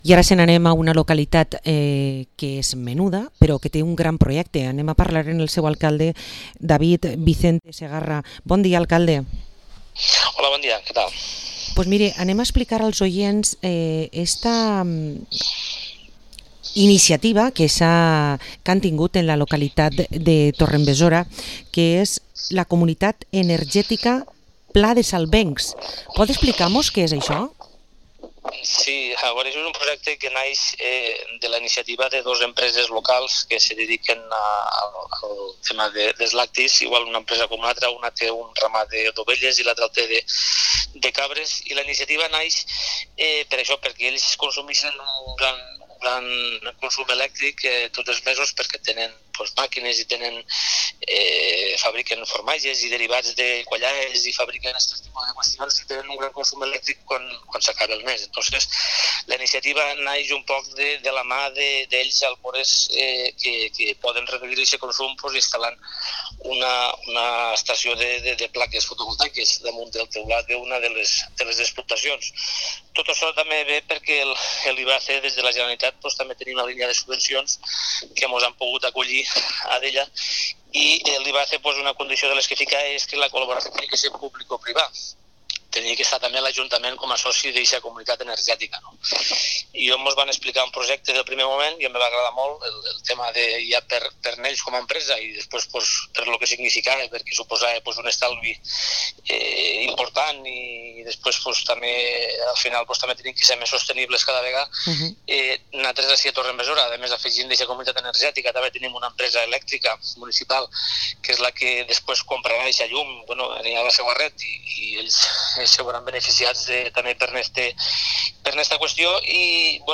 Entrevista a David Vicente Segarra, alcalde de La Torre d´En Besora